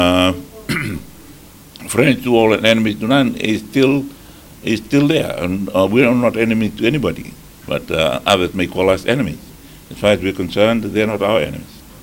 In a recent interview with FBC News, Rabuka clarified that Fiji is not seeking to distance itself from China and that their relationship is still based on the One China Policy.